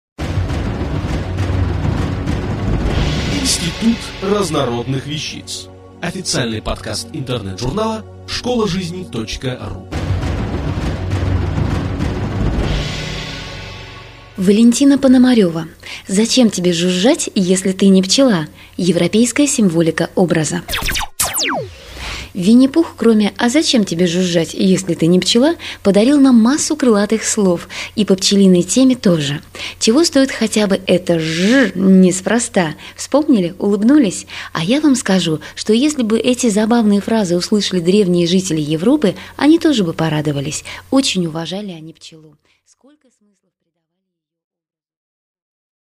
Аудиокнига Зачем тебе жужжать, если ты не пчела? Европейская символика образа | Библиотека аудиокниг